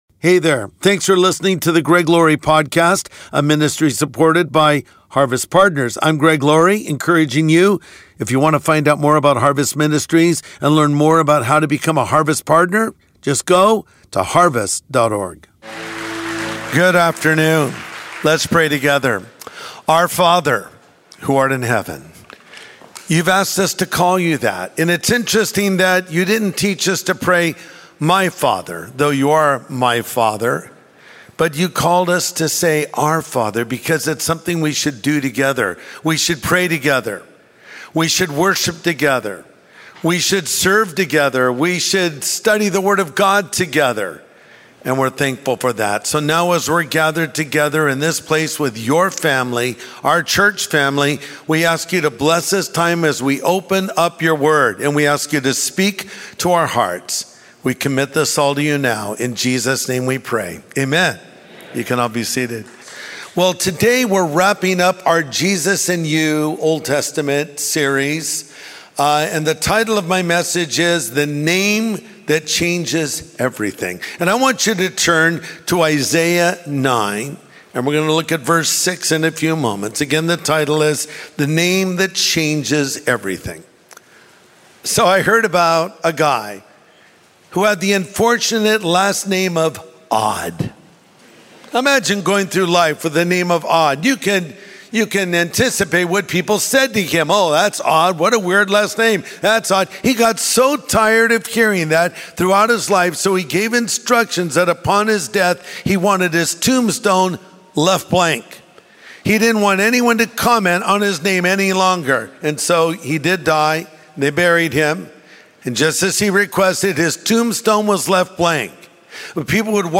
Isaiah 9:6 tells us what Jesus, the name above all names, means to us. Pastor Greg Laurie brings us insight in this message.